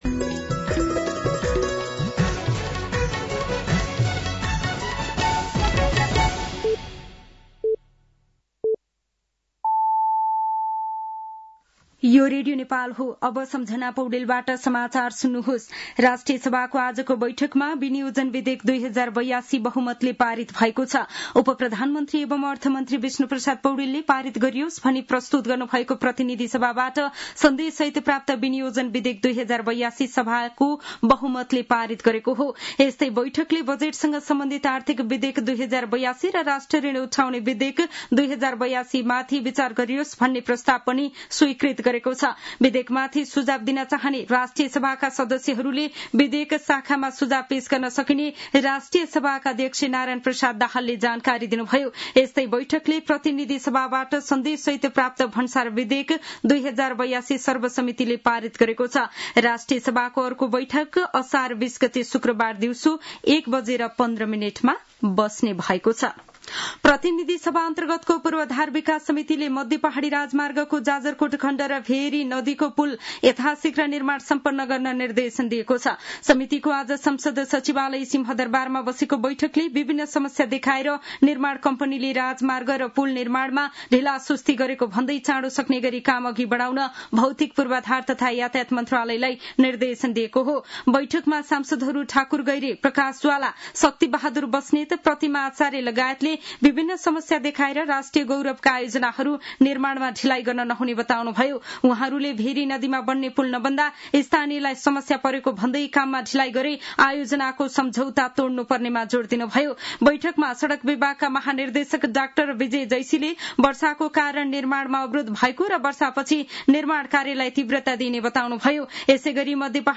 साँझ ५ बजेको नेपाली समाचार : १८ असार , २०८२
5-pm-news-3-18.mp3